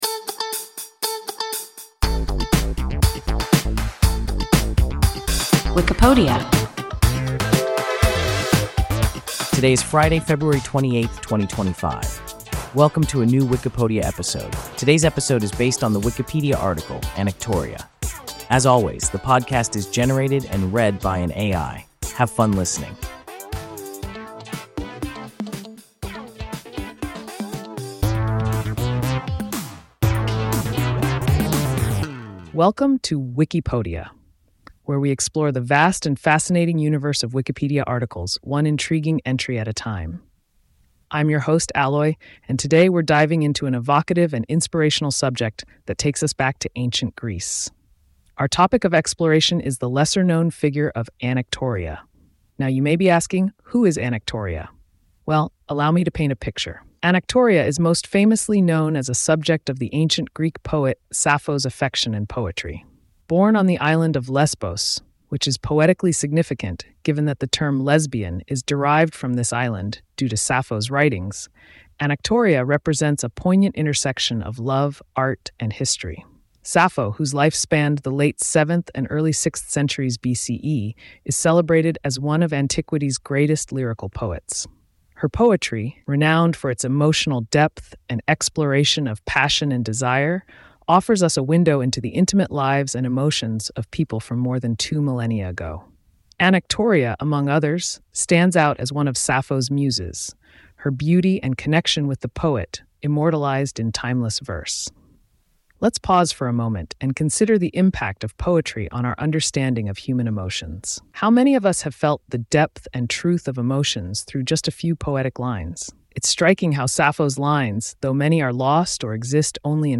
Anactoria – WIKIPODIA – ein KI Podcast